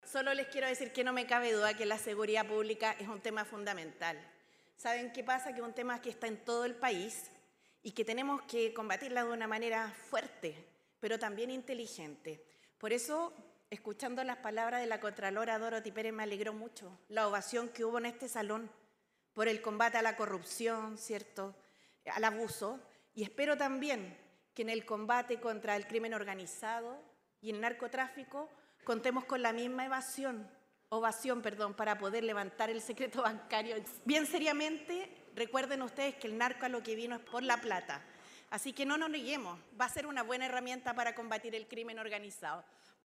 El encuentro contó con un panel presidencial que tuvo a siete de los ocho candidatos y candidatas, restándose Eduardo Artes.
Al principio, cada uno tuvo la oportunidad de dirigirse al público, y Jeanette Jara fue la primera en plantear parte de su programa, saludando a los grandes empresarios del país y enfatizando en la importancia de la seguridad pública y el levantamiento del secreto bancario.